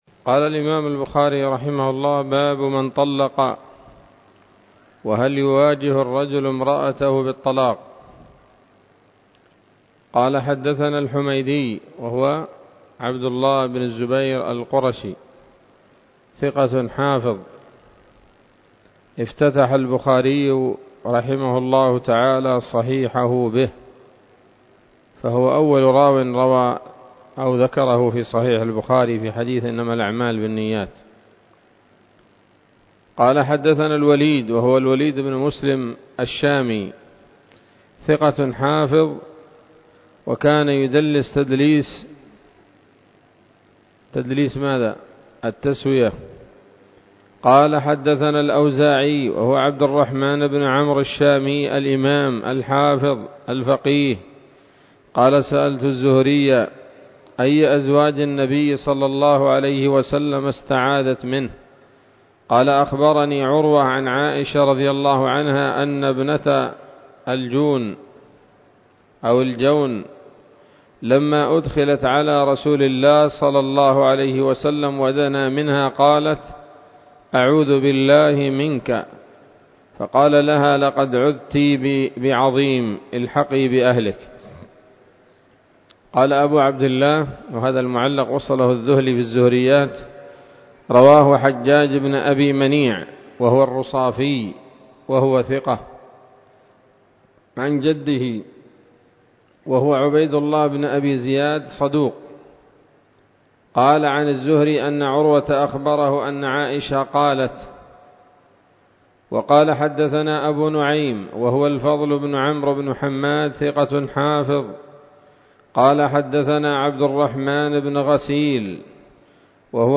الدرس الثالث من كتاب الطلاق من صحيح الإمام البخاري